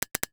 NOTIFICATION_Click_08_mono.wav